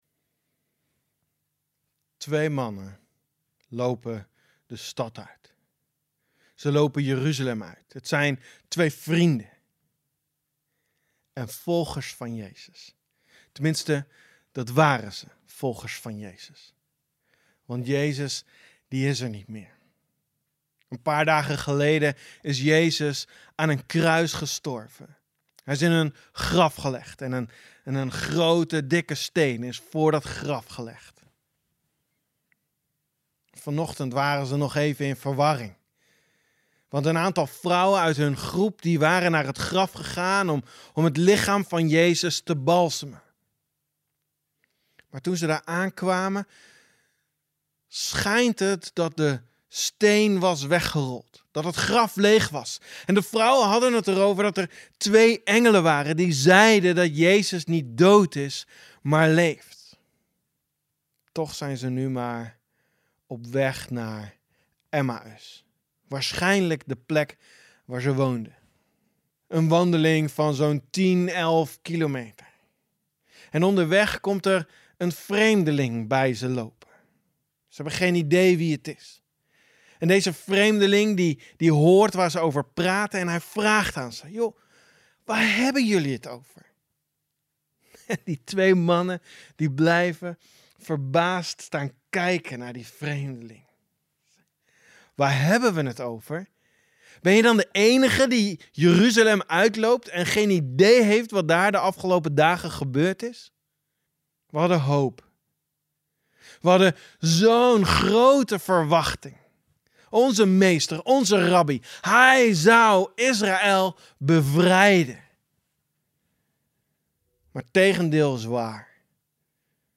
Jeugddienst preek Lukas 24 - Berea De Maten